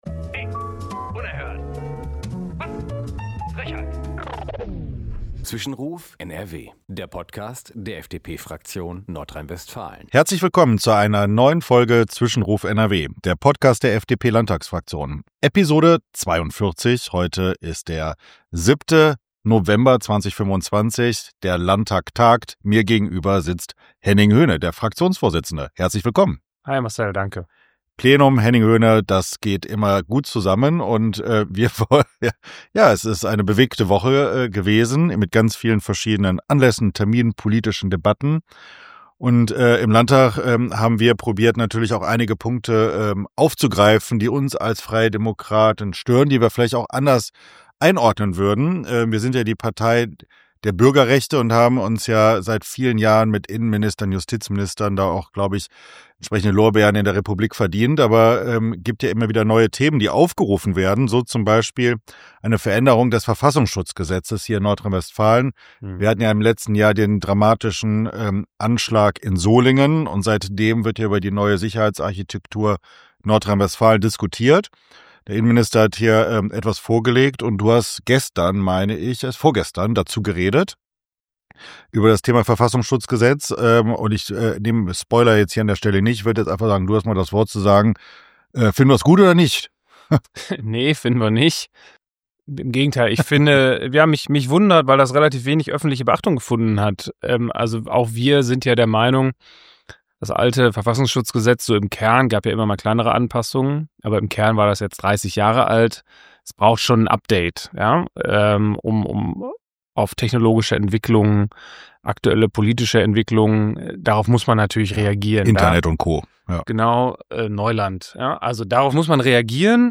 Henning Höne (Fraktionsvorsitzender) und Marcel Hafke (Parlamentarischer Geschäftsführer) diskutieren in Folge 42 von Zwischenruf NRW wieder über aktuelle Debatten der Plenarwoche in Nordrhein-Westfalen.